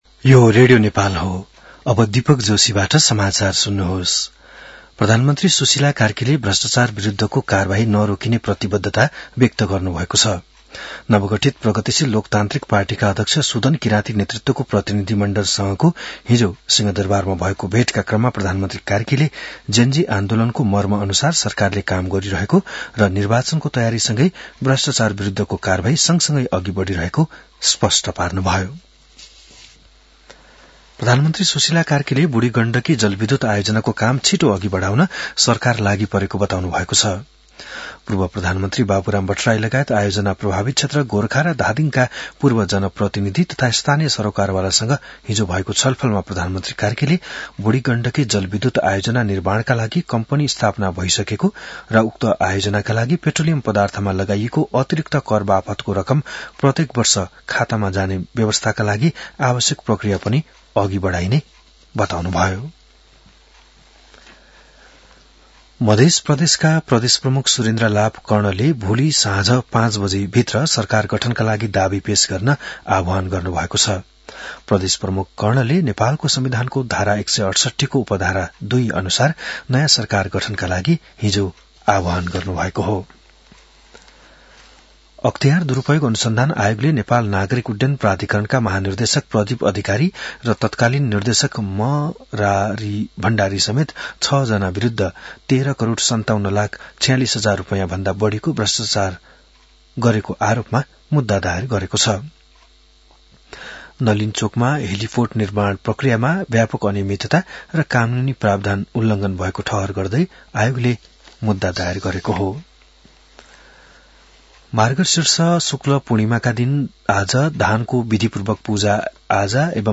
बिहान १० बजेको नेपाली समाचार : १८ मंसिर , २०८२